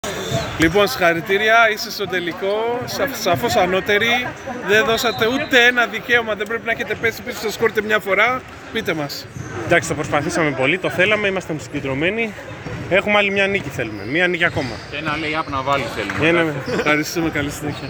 Δηλώσεις Πρωταγωνιστών: